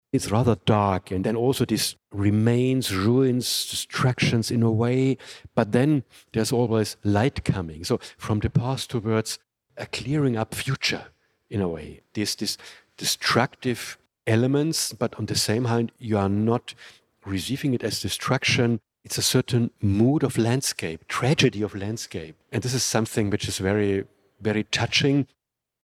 Listen as they describe the artwork in their own words.